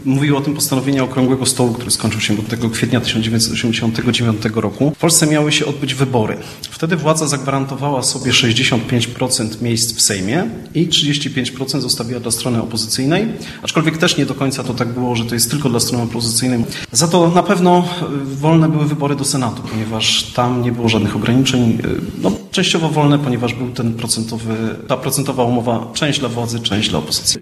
Ponieważ audycja prowadzona była na żywo z siedziby Muzeum Historycznego w Ełku, gdzie do 9 czerwca możecie zwiedzać wystawę „Wybory ’89 w Ełku”.